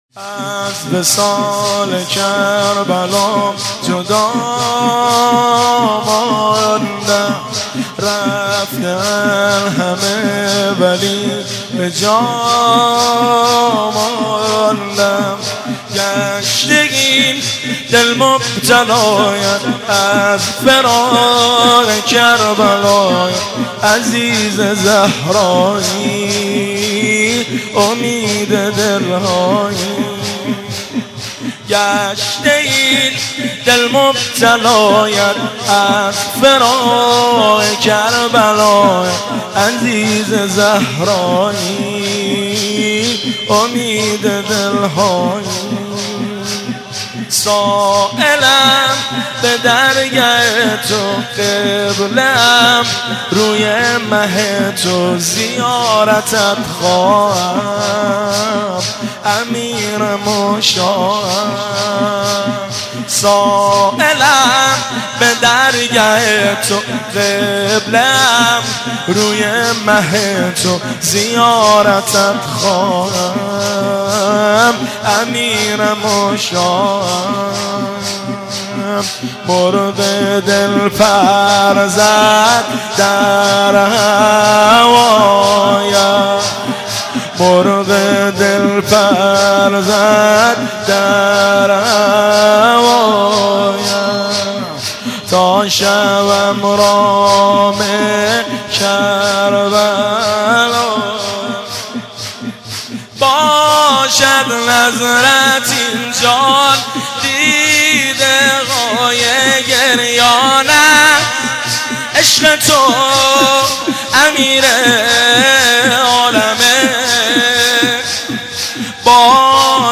روضه و ذکر